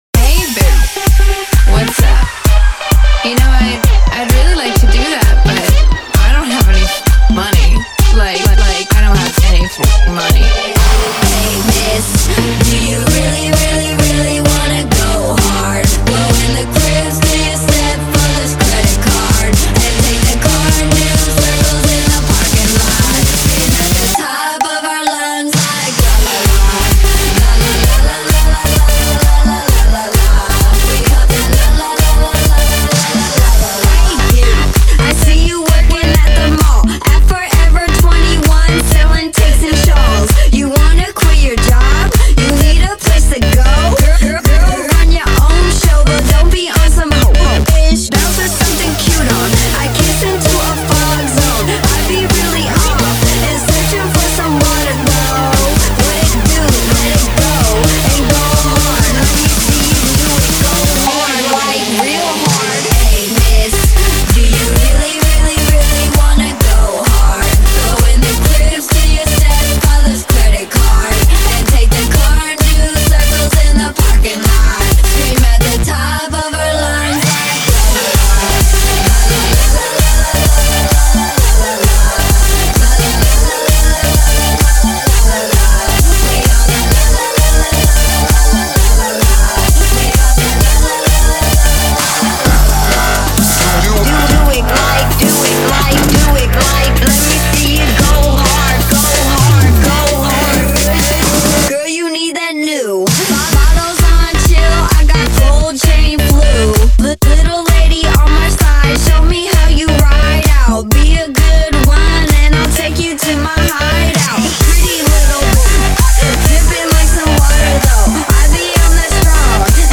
Файл в обменнике2 Myзыкa->DJ's, транс
Жанр: Club/Dance; Битрэйт